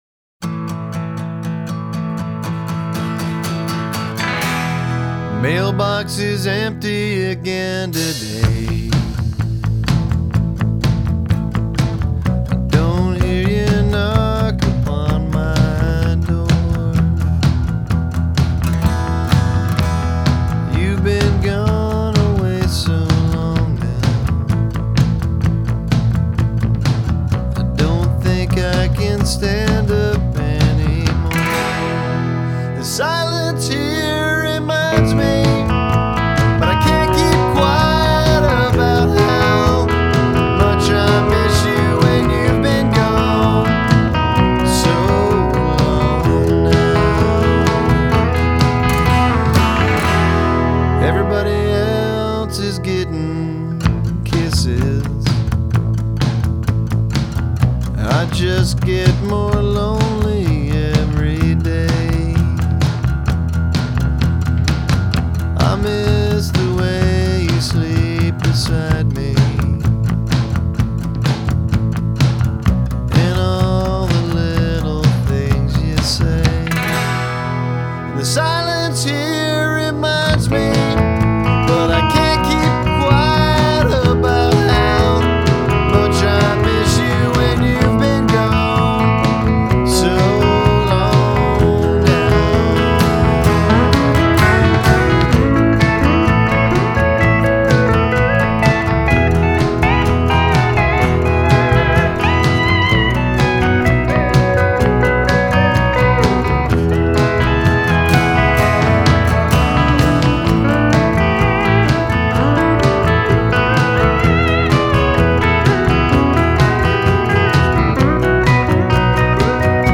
has much more of that country feel to it